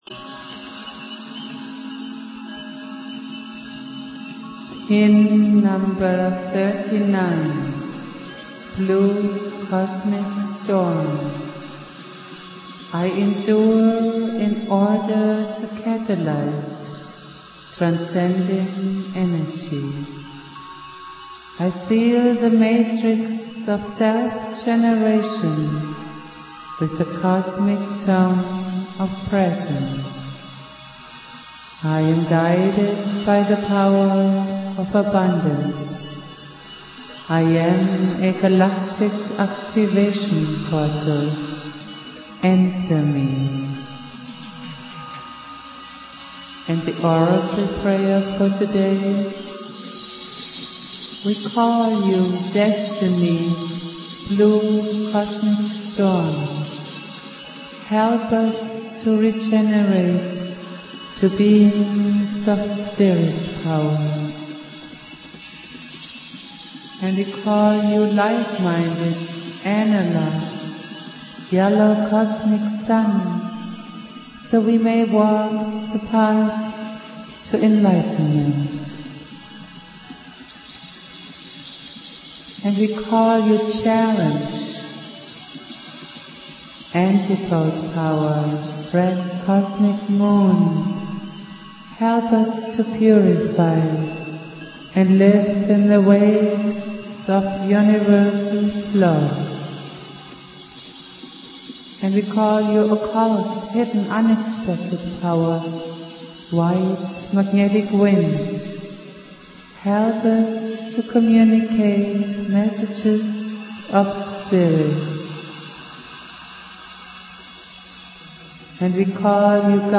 flute
Prayer